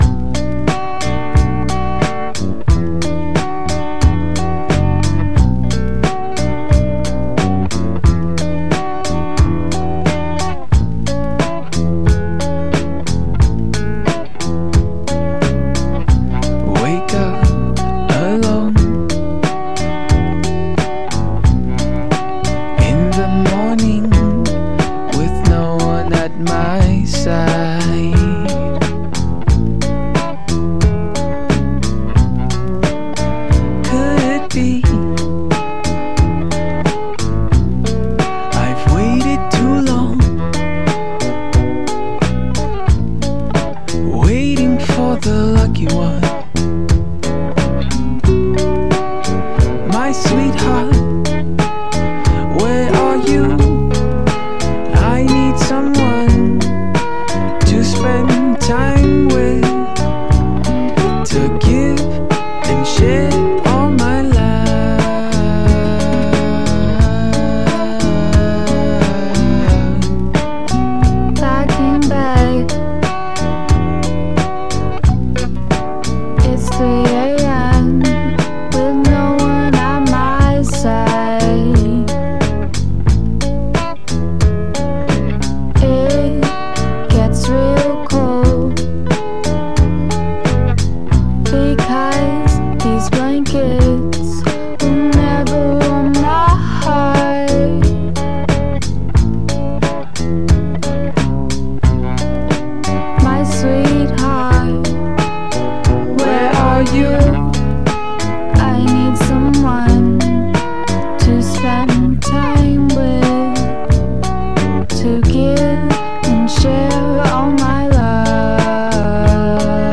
Indie Indie pop Pop rock